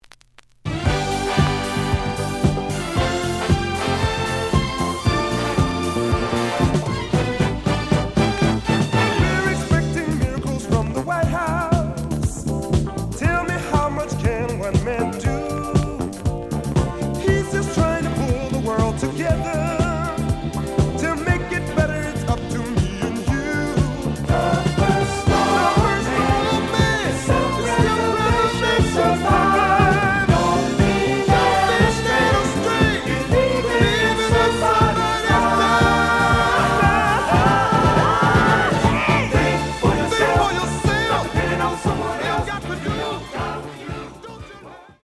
The audio sample is recorded from the actual item.
●Format: 7 inch
●Genre: Disco
Slight edge warp. But doesn't affect playing.